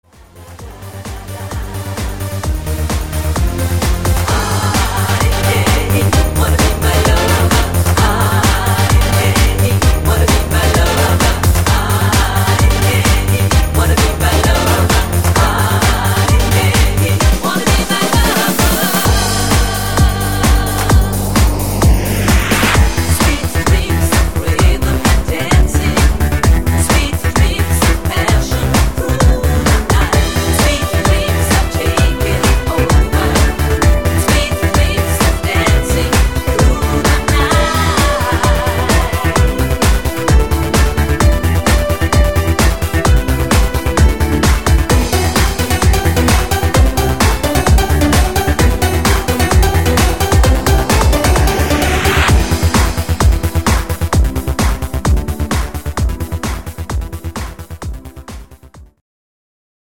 Easy to sing